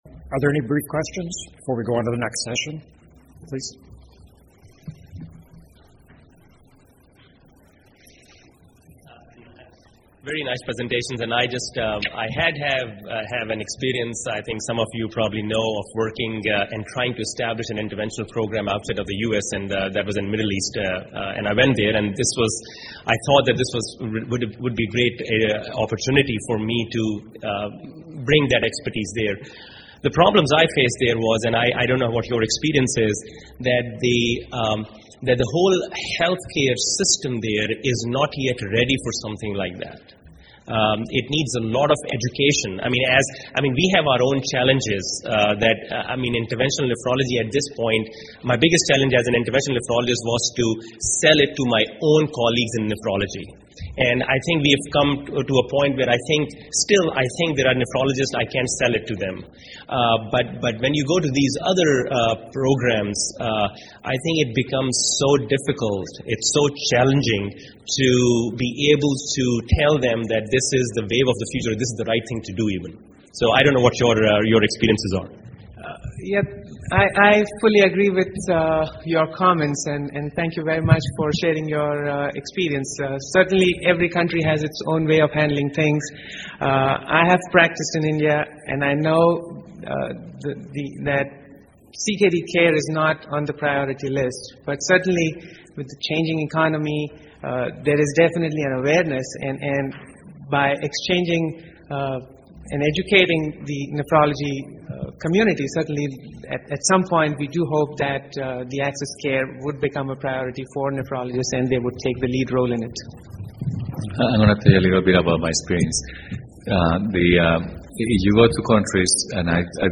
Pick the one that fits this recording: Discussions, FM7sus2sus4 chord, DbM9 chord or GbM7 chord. Discussions